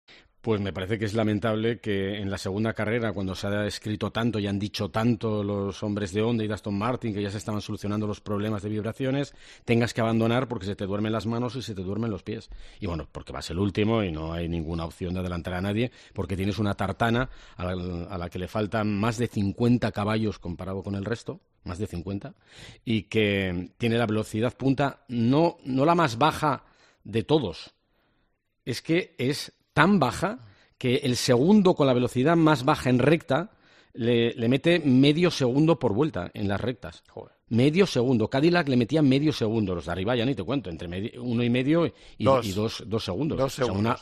La situación ha provocado la dura reacción del periodista especializado Antonio Lobato, que ha calificado de "lamentable" que estos problemas ocurran en la segunda carrera, sobre todo después de que Aston Martin y Honda aseguraran que se estaban solucionando.